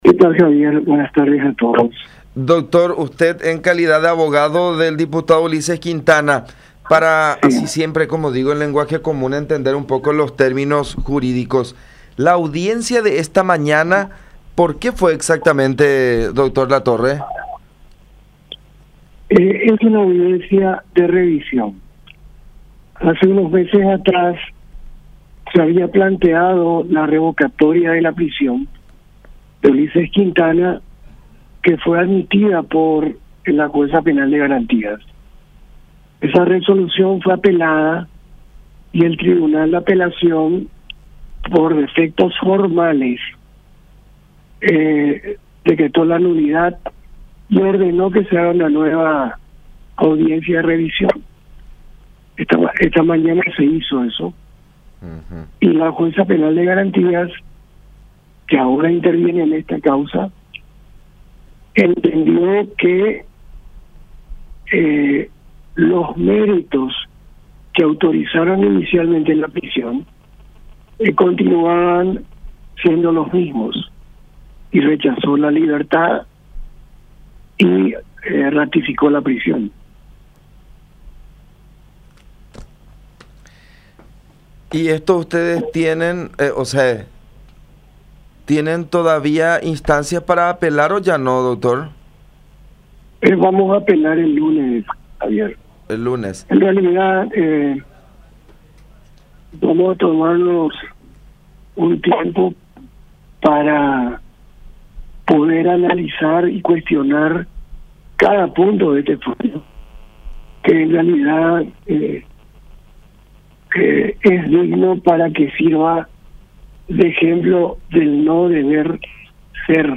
en comunicación con La Unión R800 AM